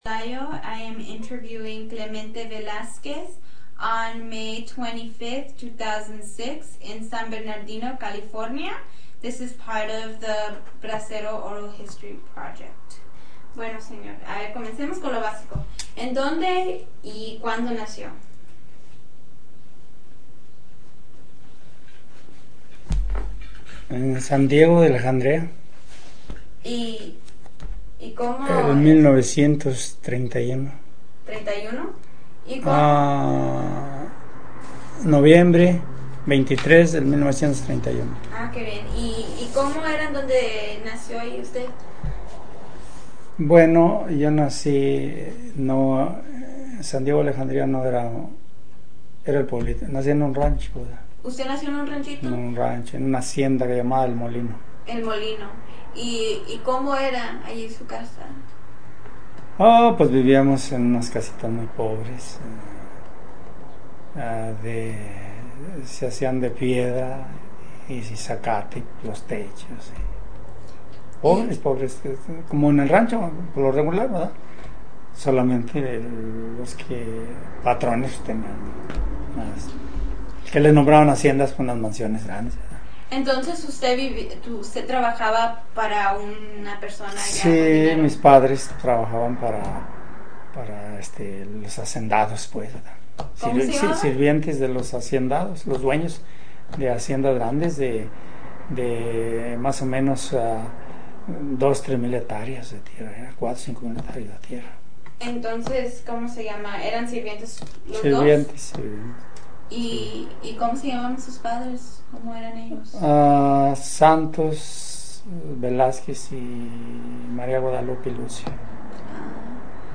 Biographical Synopsis of Interviewee